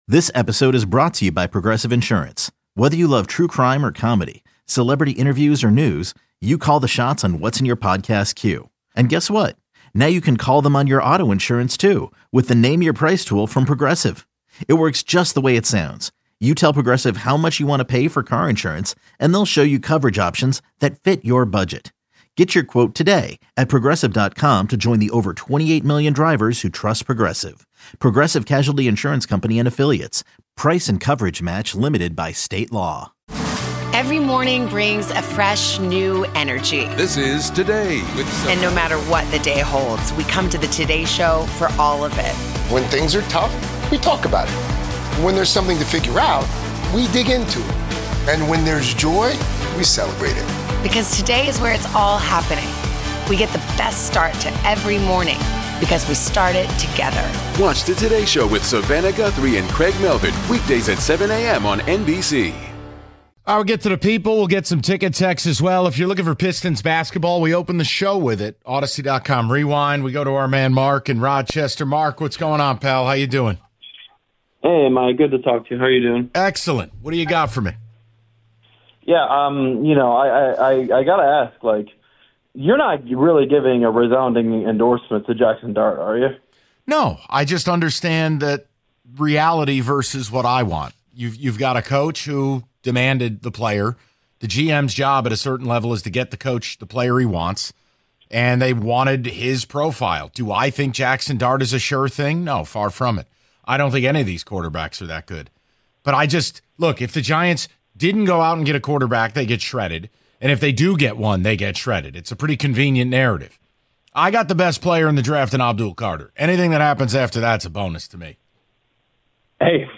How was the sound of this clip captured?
Taking Your Calls On Lions' Day 2 Wishlist